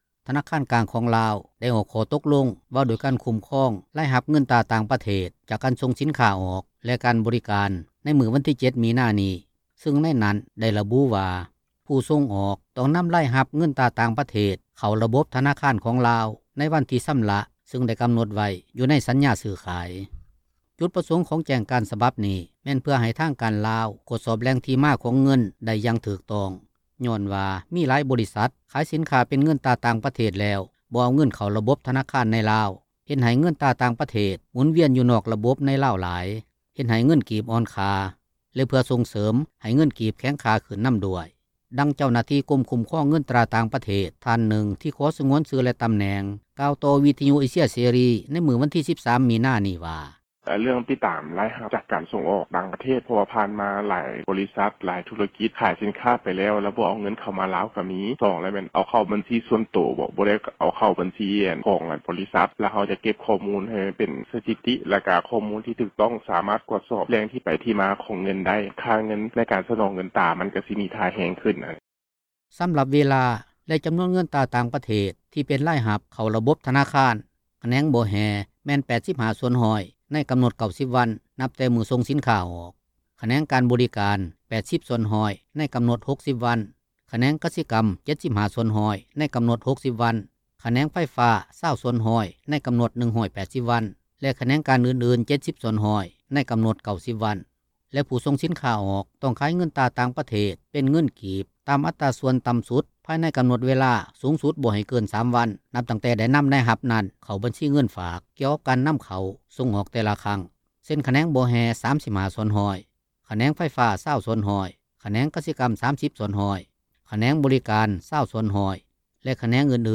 ດັ່ງເຈົ້າໜ້າທີ່ ກົມຄຸ້ມຄອງເງິນຕຣາຕ່າງປະເທດທ່ານນຶ່ງ ທີ່ຂໍສງວນຊື່ ແລະຕໍາແໜ່ງ ກ່າວຕໍ່ວິທຍຸເອເຊເສຣີ ໃນມື້ວັນທີ 13 ມີນານີ້ວ່າ:
ກ່ຽວກັບເຣື່ອງທີ່ວ່ານີ້ ນັກວິຊາການ ທີ່ກ່ຽວຂ້ອງທ່ານນຶ່ງ ເວົ້າວ່າ ເປັນເຣື່ອງດີ ທີ່ຣັຖບານພະຍາຍາມກະຕຸ້ນເສຖກິຈ-ການເງິນໃນລາວ ໃຫ້ເຂັ້ມແຂງຂຶ້ນ ໂດຍໃຫ້ພາກທຸຣະກິຈ ມີສ່ວນຮ່ວມ.
ພ້ອມດຽວກັນນັ້ນ ຜູ້ປະກອບການຄ້າທ່ານນຶ່ງ ກໍເວົ້າວ່າ ເຣື່ອງຄໍາສັ່ງທີ່ວ່ານັ້ນ ເມື່ອຮູ້ແລ້ວ ກໍຕ້ອງປະຕິບັດຕາມ ເພາະຖືວ່າເປັນເຣື່ອງດີ ທີ່ຈະເຮັດໃຫ້ເງິນກີບແຂງຄ່າຂຶ້ນ ແຕ່ກໍຍັງມີຄວາມສ່ຽງ ທີ່ອາດຂາດທຶນຢູ່.